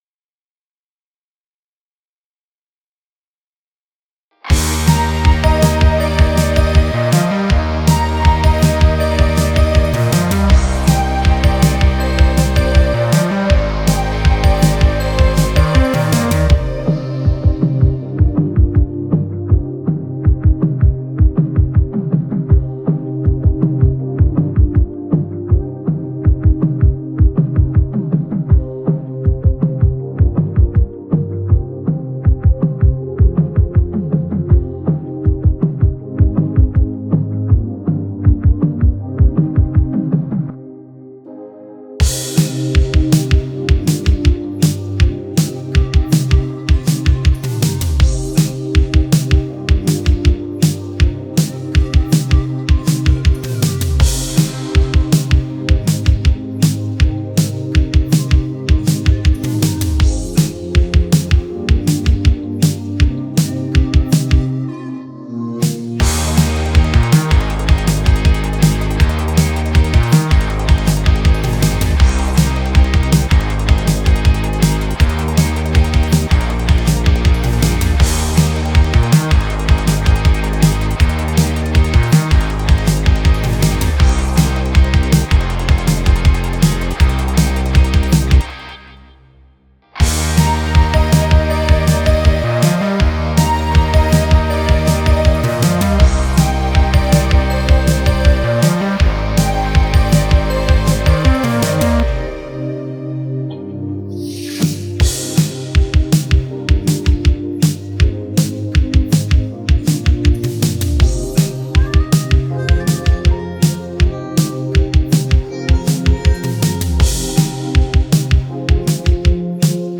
פלייבק חסידי
נאמן למקור